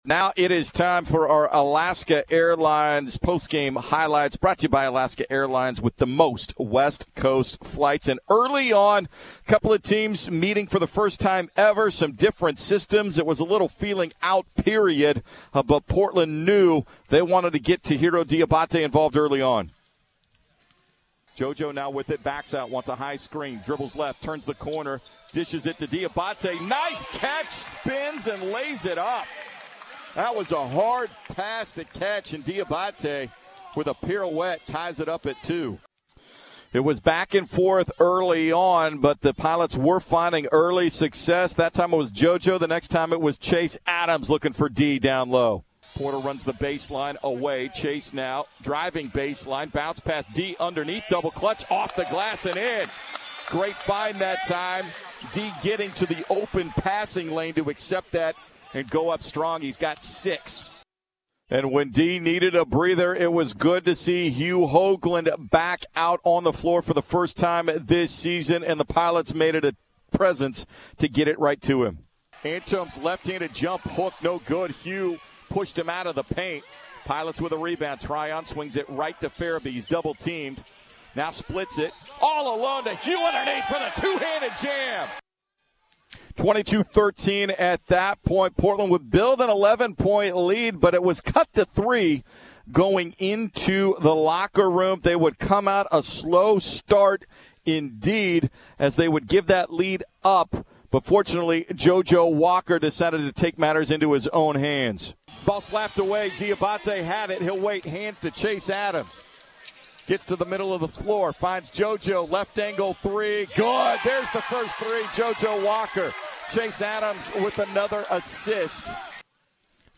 Men's Hoops Post-Game Highlights vs. Maine